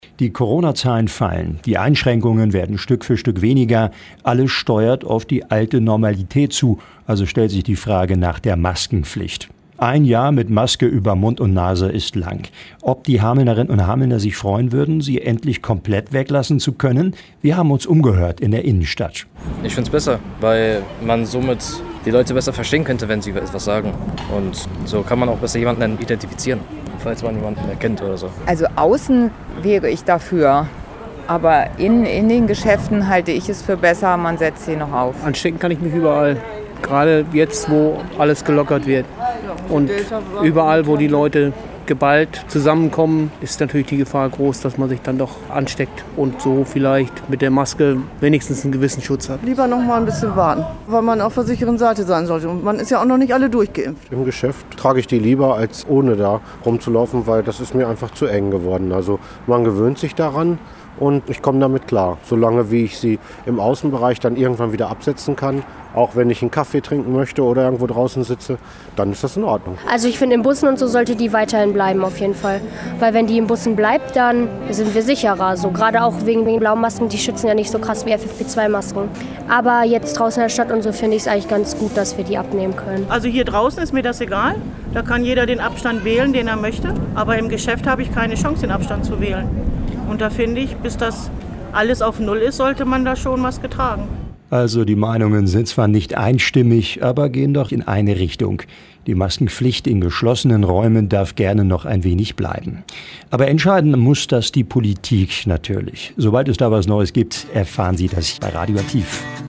Landkreis Hameln-Pyrmont: UMFRAGE MASKENPFLICHT
landkreis-hameln-pyrmont-umfrage-maskenpflicht.mp3